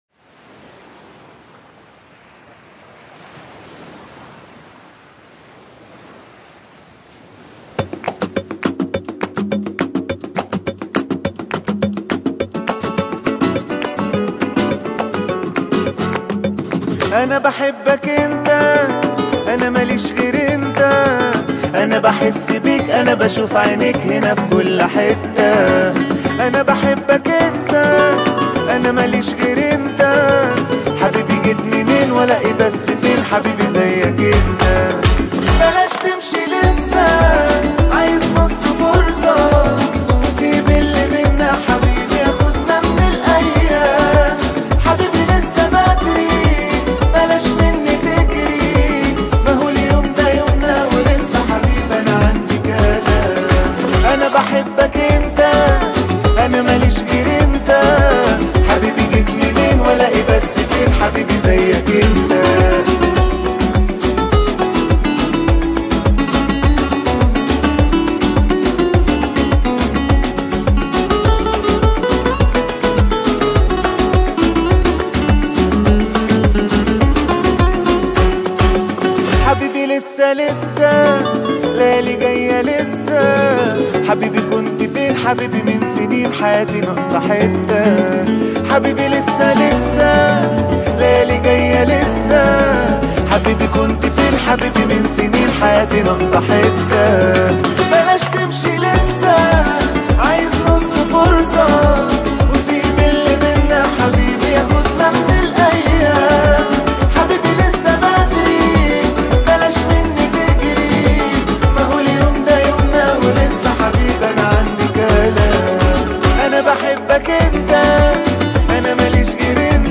اغانى رومانسيه